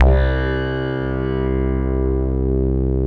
53 BASS 1 -R.wav